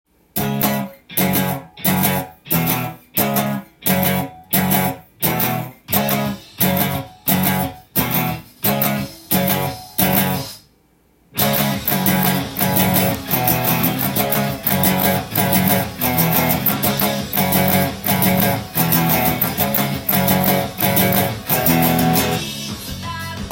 音源にあわせて譜面通り弾いてみました
は、C♯ｍ７／Ｆ♯７／Ｆ♯ｍ７／Ｅ
オリジナルギターパートよりもさらに簡単にしたパワーコード